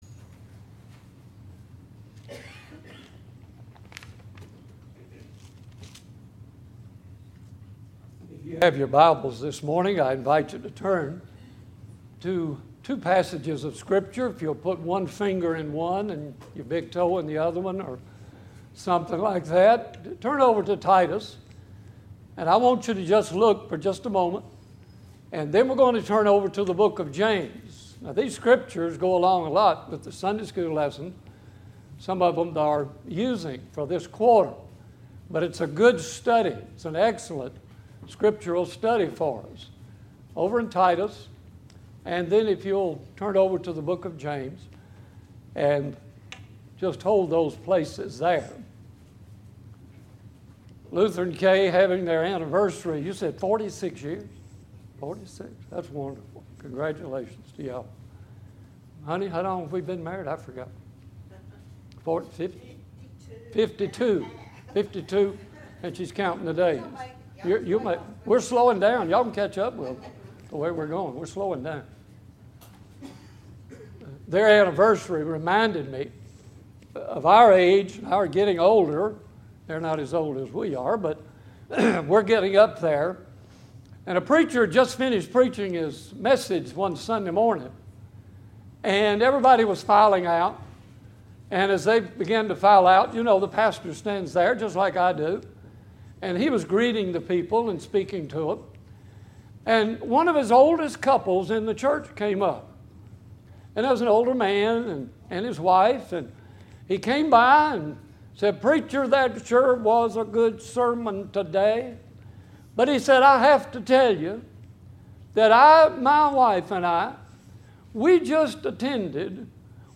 Autaugaville Baptist Church Sermons